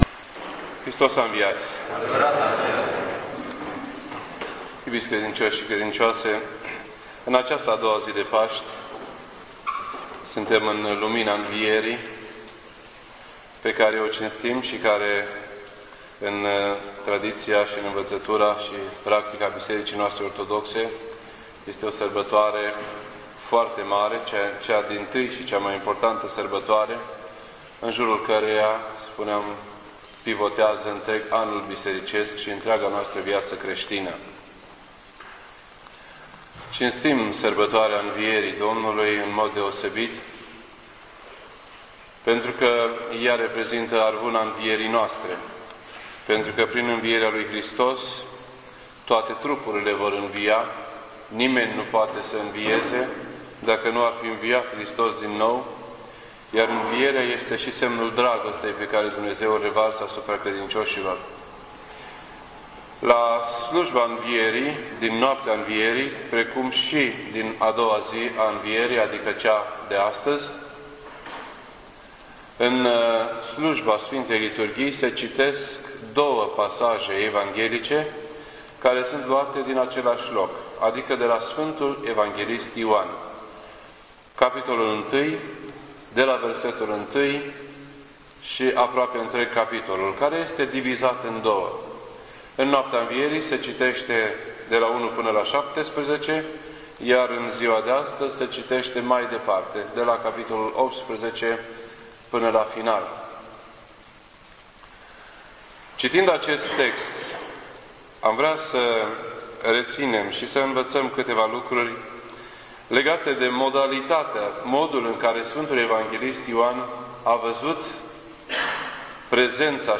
This entry was posted on Sunday, April 8th, 2007 at 10:20 AM and is filed under Predici ortodoxe in format audio.